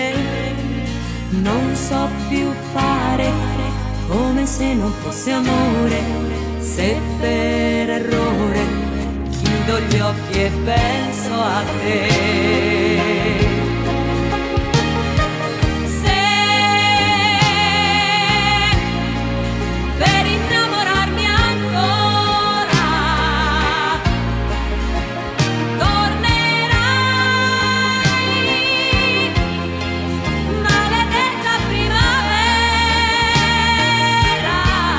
Жанр: Поп музыка